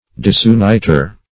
Disuniter \Dis`u*nit"er\